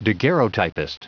Prononciation du mot daguerreotypist en anglais (fichier audio)